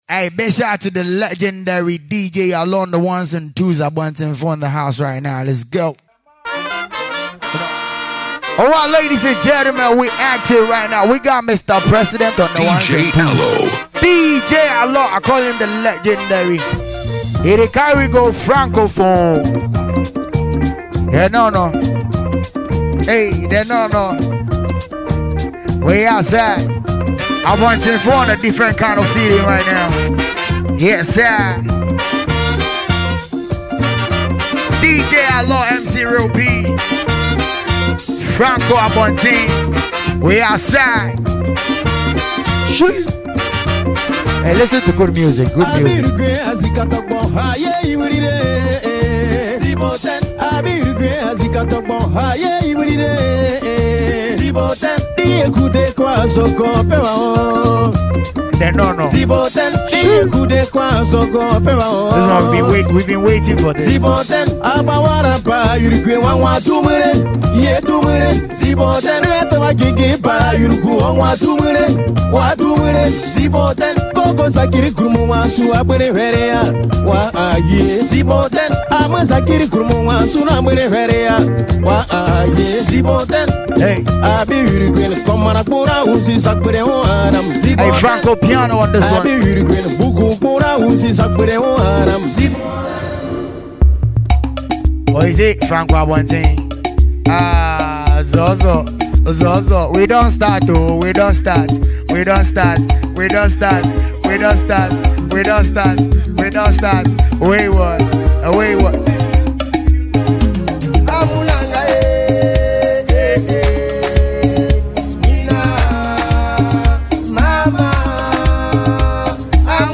DJ Mixtape
Ghana Afrobeat MP3